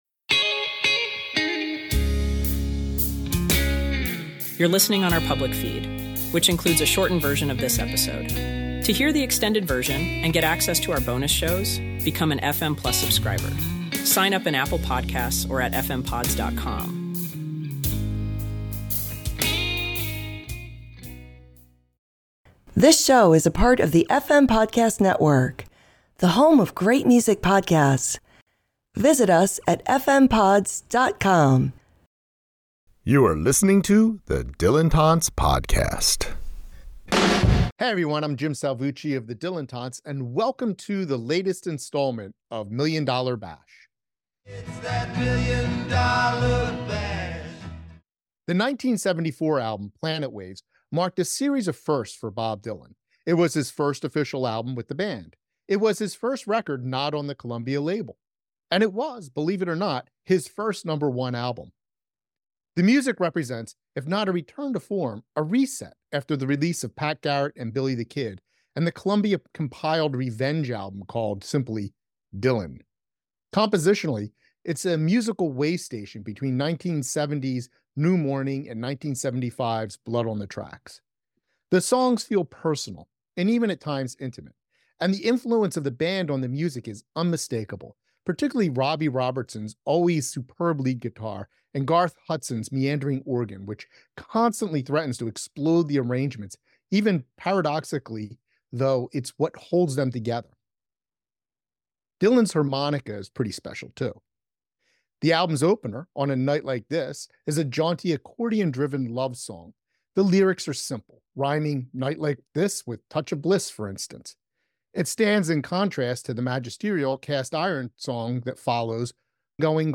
A Million $ Bash Roundtable The 1974 album Planet Waves marked a series of firsts for Bob Dylan.